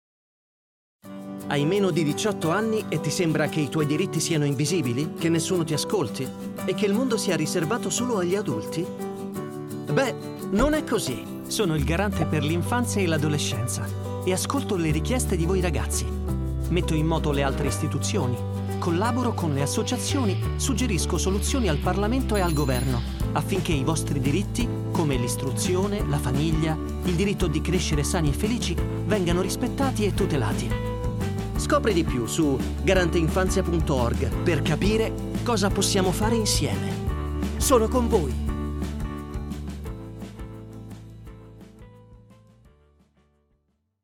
Muestras de voz nativa
Vídeos corporativos
Adulto joven